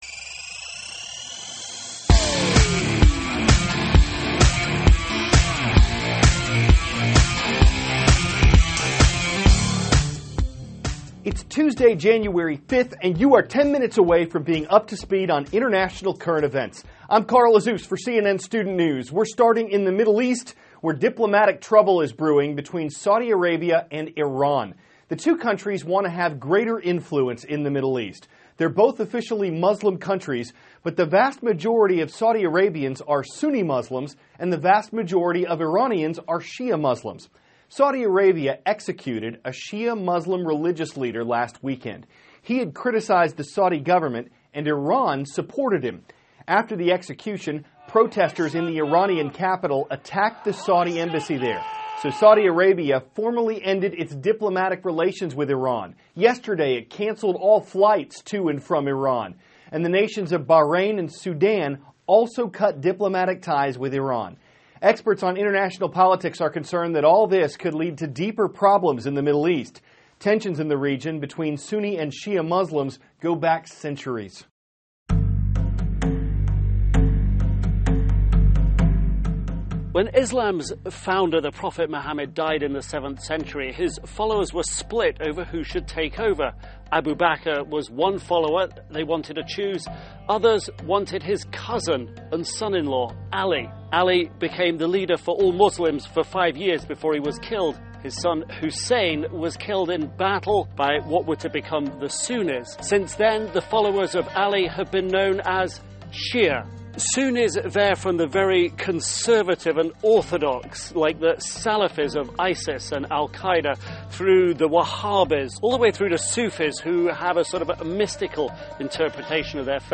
*** CARL AZUZ, cnn STUDENT NEWS ANCHOR: It`s Tuesday, January 5th, and you are 10 minutes away from being up to speed on international current events.